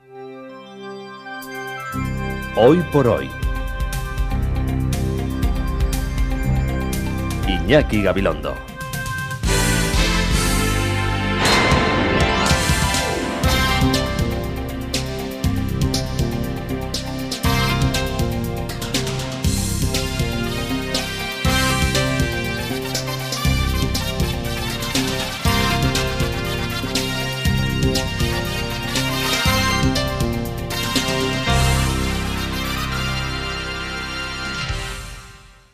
Indicatiu del programa i capçalera musical